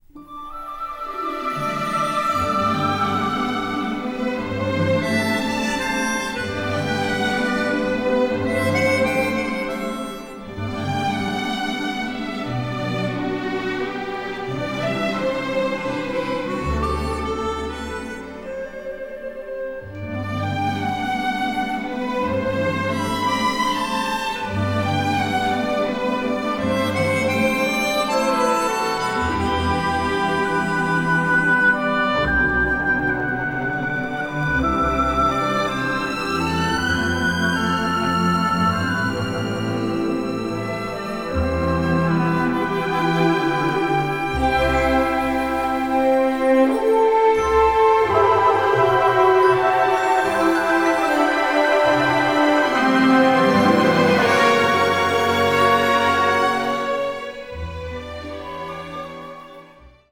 exciting, classic symphonic adventure score